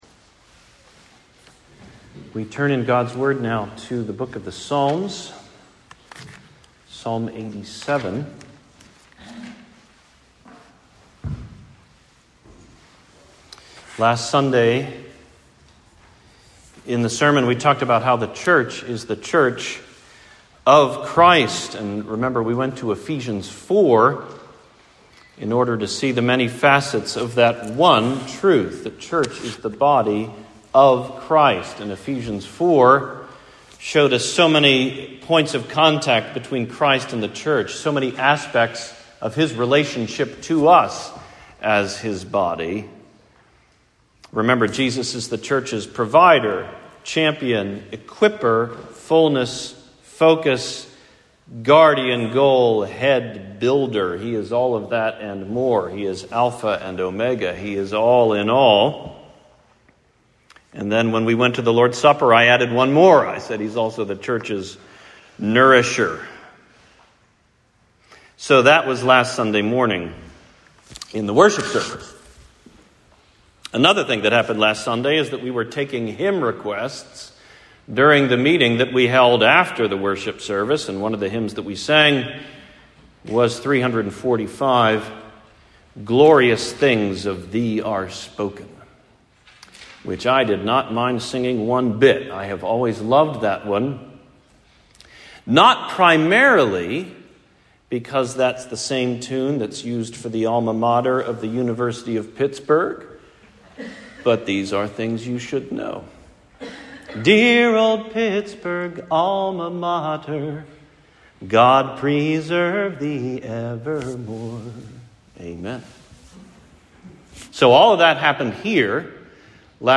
Glorious Things of Thee Are Spoken: Sermon on Psalm 87